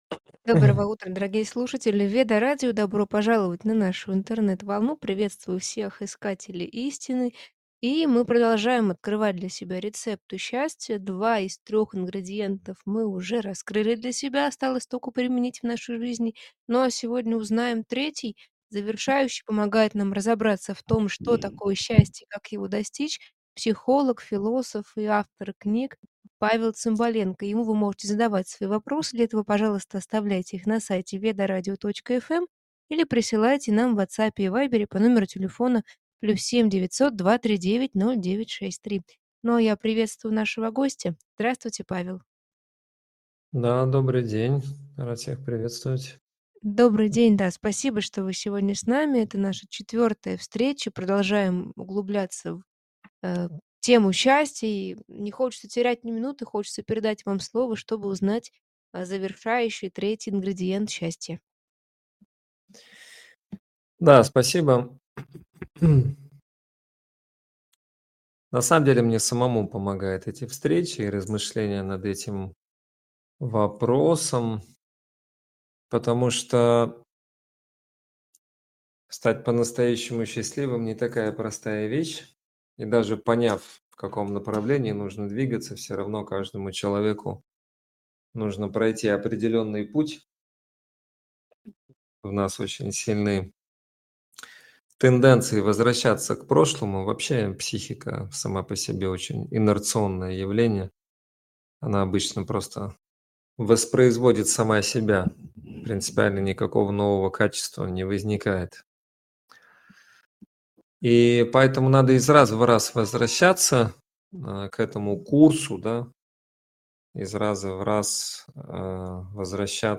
00:00 — Приветствие слушателей на Ведарадио и объявление о продолжении обсуждения рецепта счастья. 02:00